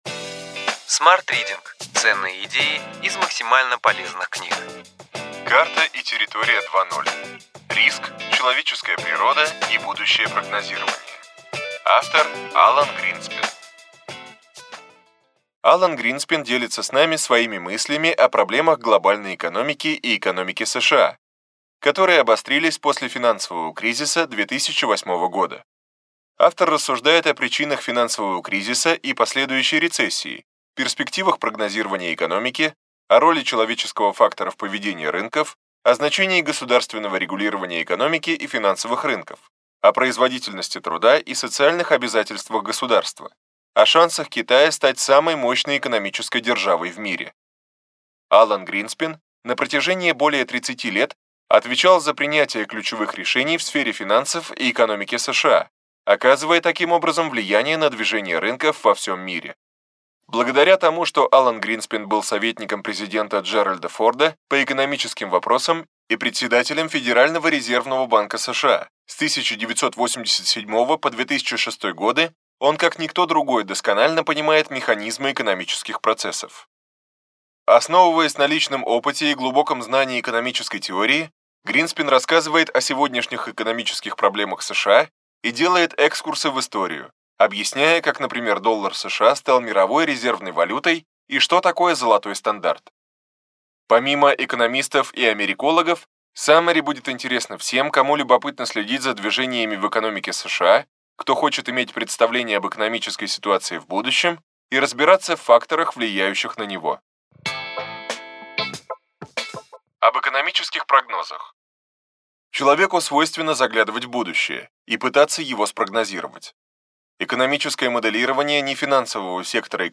Аудиокнига Ключевые идеи книги: Карта и территория 2.0. Риск, человеческая природа и будущее прогнозирования.